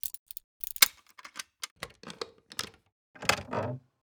Wood_Picks.ogg